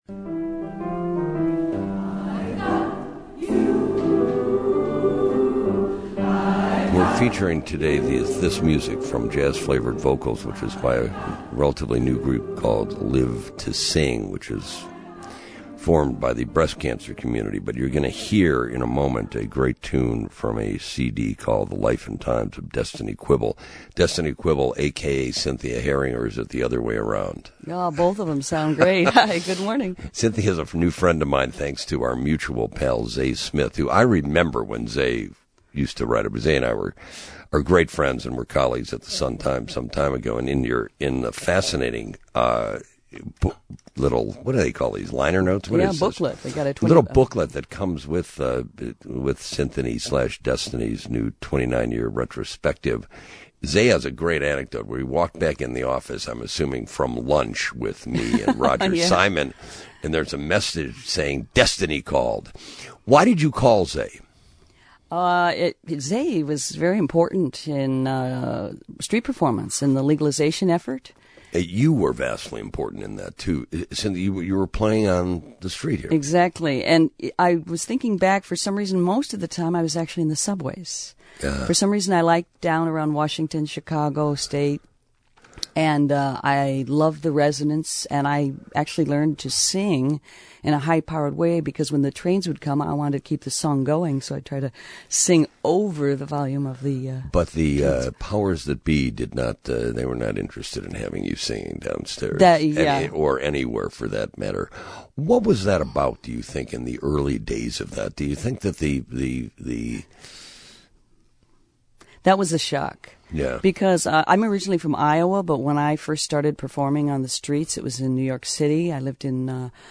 WGN Chicago
Here are the audio files from the interview.